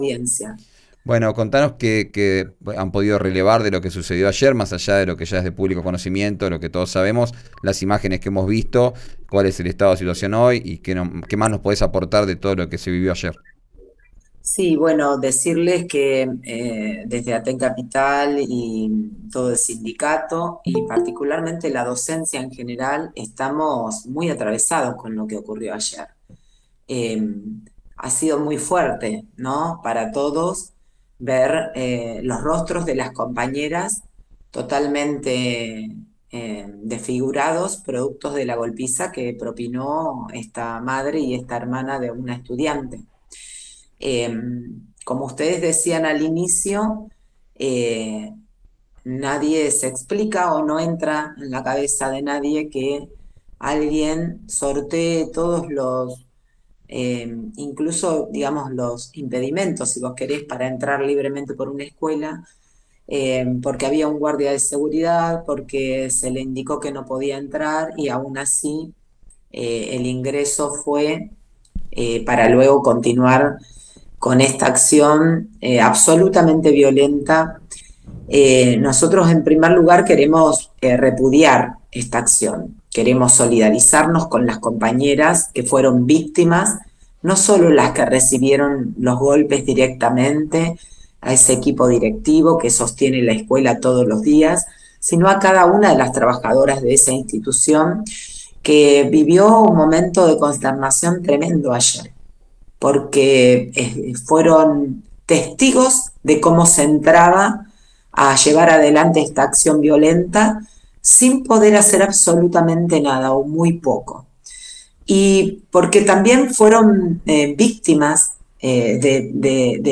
En una entrevista con RÍO NEGRO RADIO